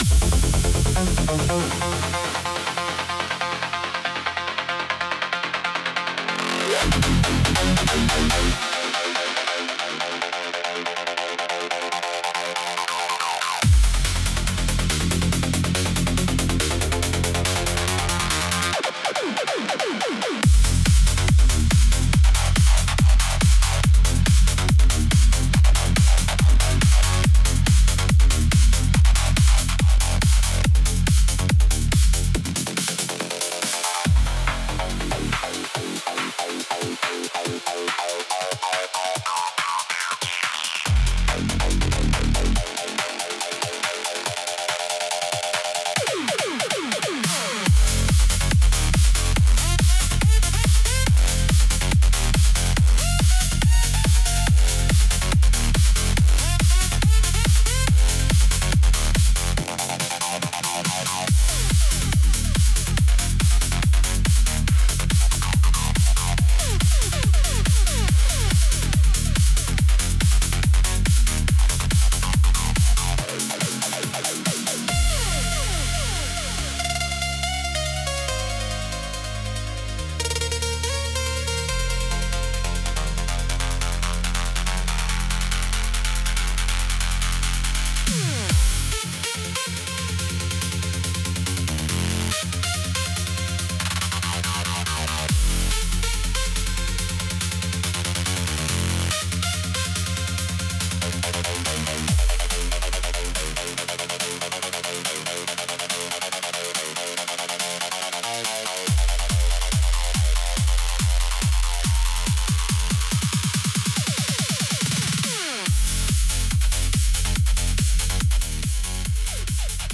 イメージ：インスト,メタル,テクノ,Remix
インストゥルメンタル（instrumental）